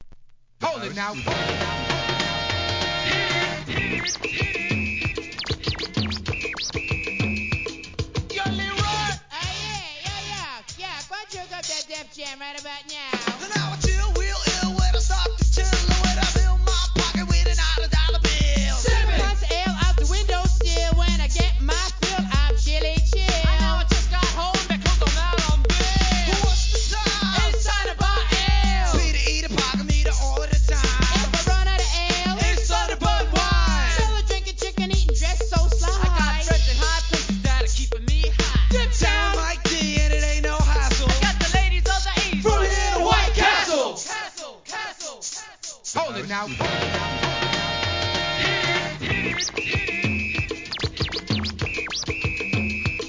HIP HOP/R&B
OLD SCHOOL!!